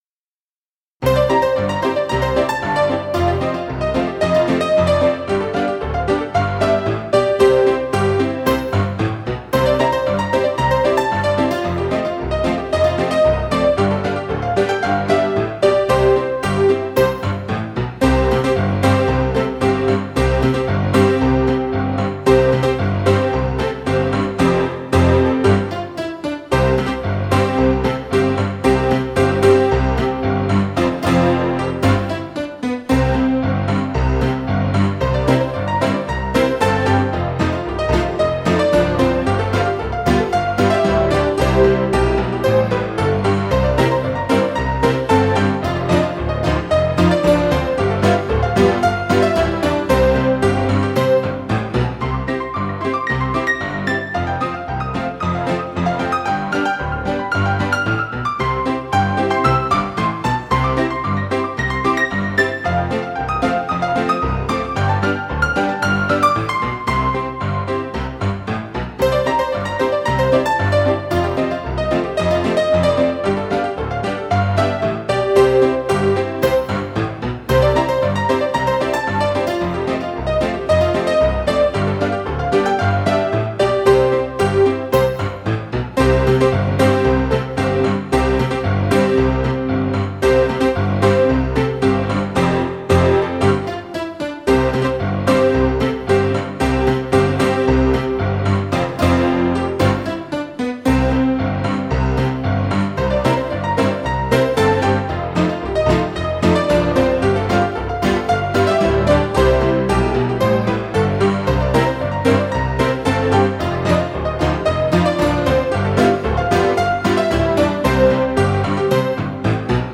recorded from a Roland Sound Canvas SC-55mkII